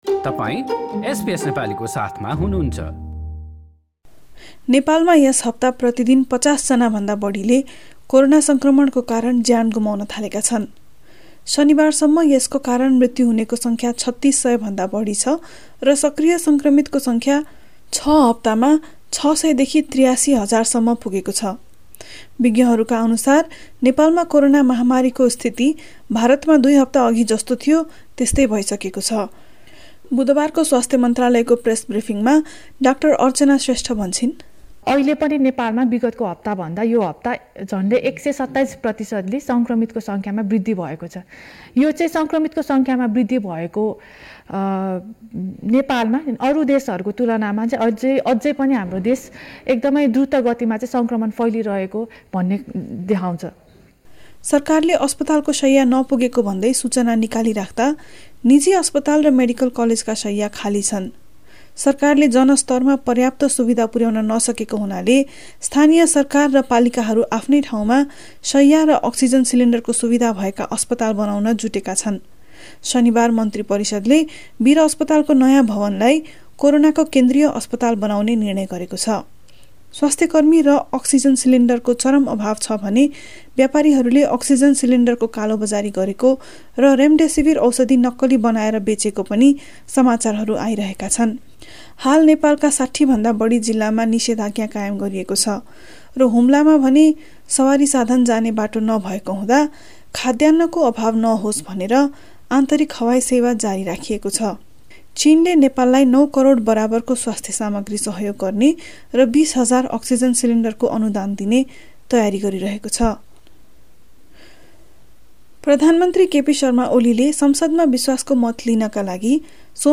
A text version of the news report is available in the Nepali language version of our website.